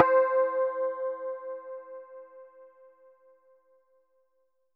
SPOOKY C4.wav